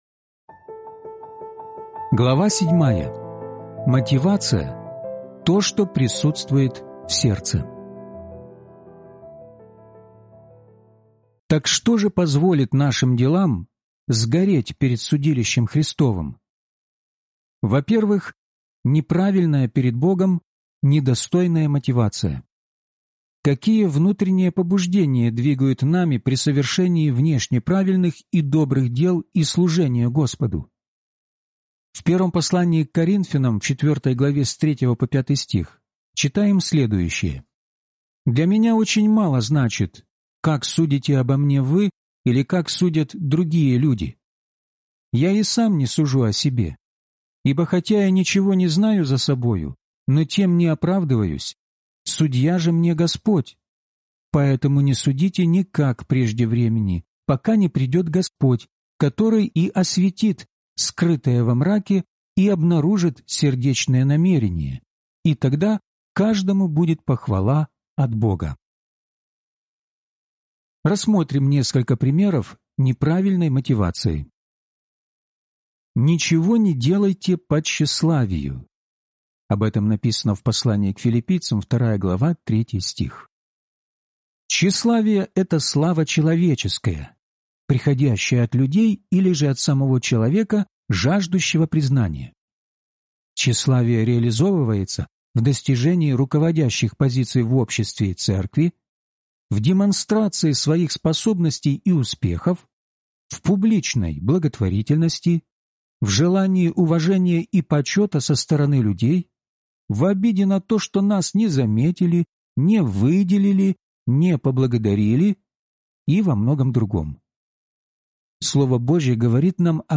Судилище Христово (аудиокнига) - День 7 из 12